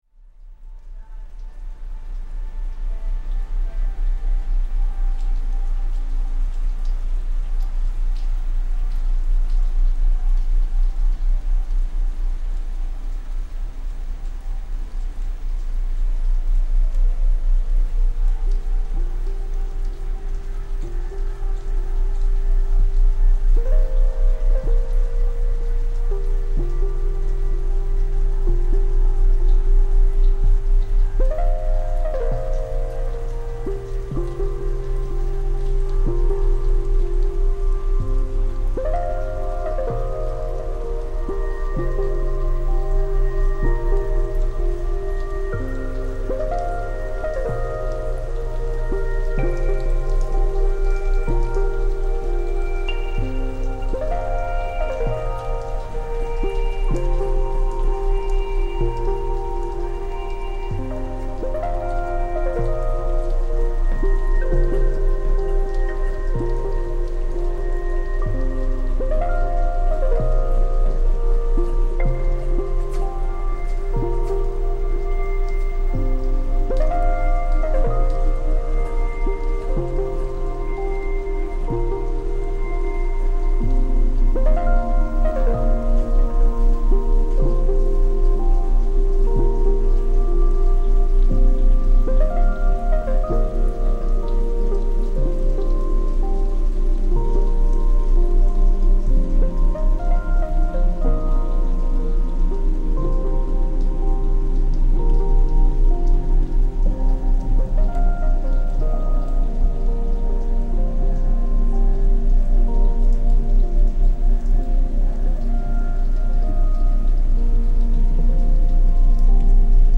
Ferry across the Baltic Sea reimagined